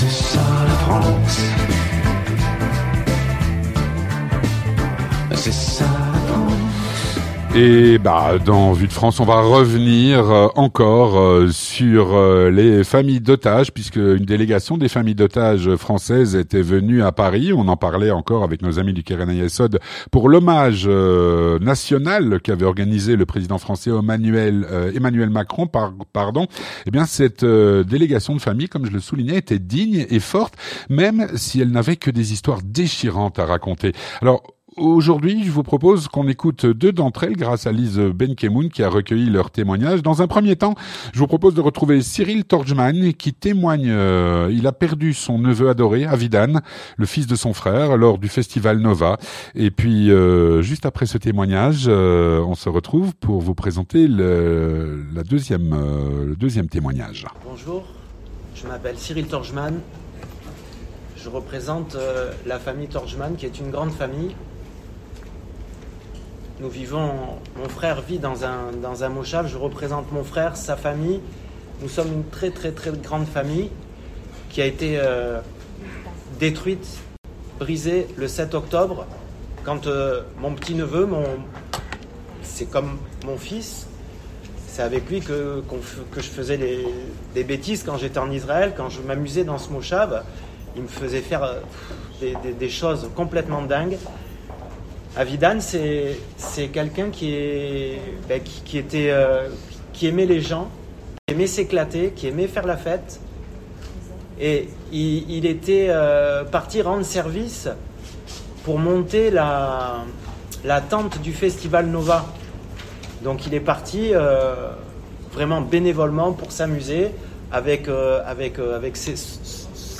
Témoignages de familles de jeunes israéliens tombés aux combats (09/02/24)